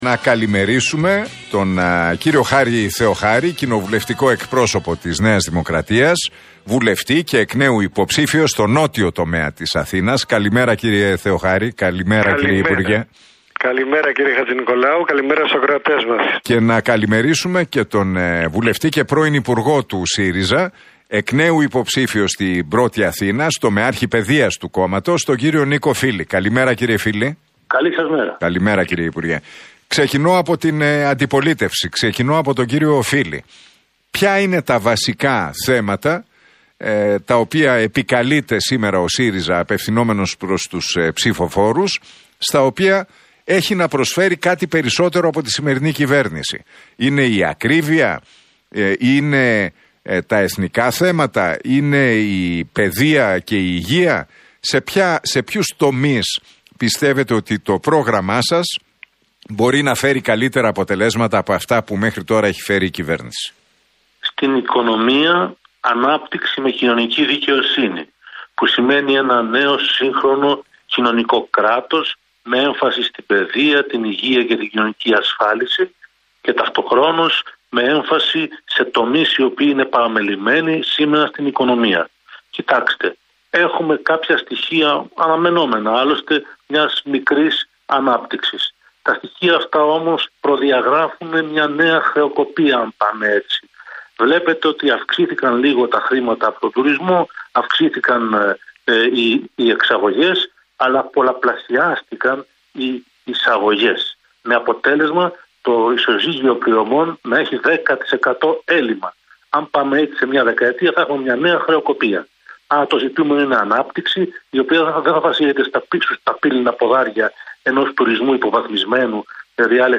Debate στον αέρα του Realfm 97,8. Τα ξίφη τους διασταύρωσαν στην εκπομπή του Νίκου Χατζηνικολάου, ο κοινοβουλευτικός εκπρόσωπος της ΝΔ, Χάρης Θεοχάρης και ο βουλευτής του ΣΥΡΙΖΑ, Νίκος Φίλης.